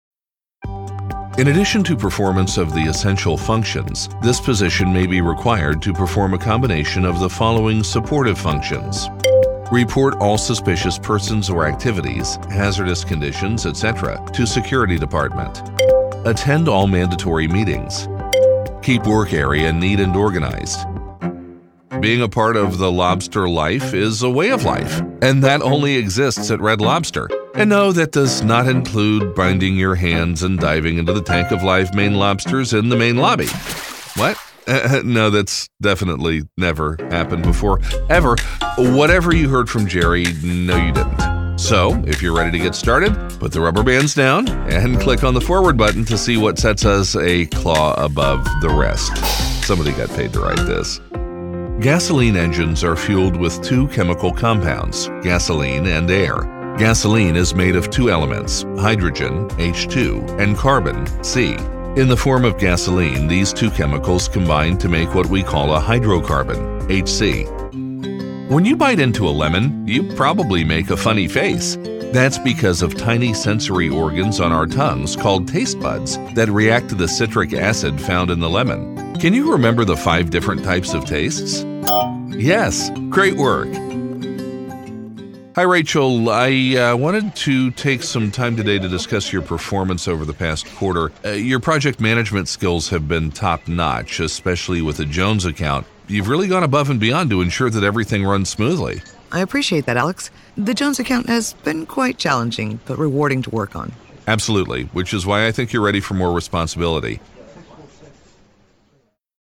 Trustworthy, Lively, Guy next door.
eLearning